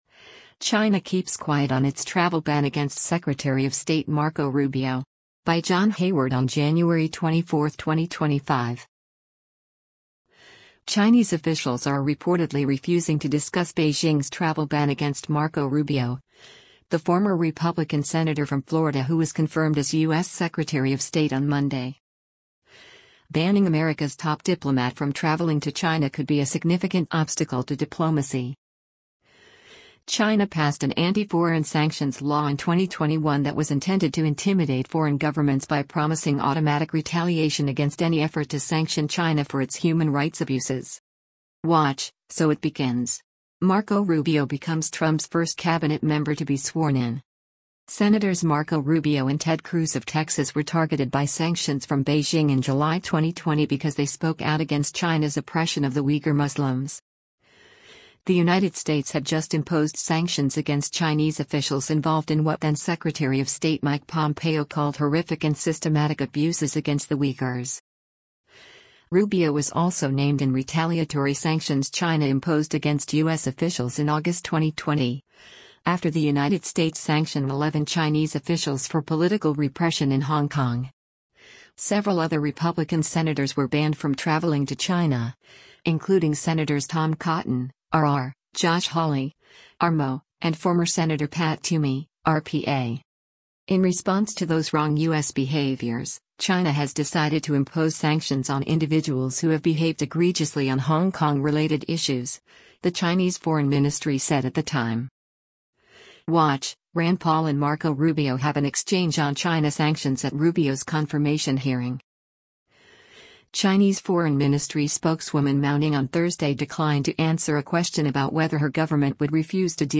Gaza - Secretary of State Marco Rubio speaks after being sworn in by Vice President JD Van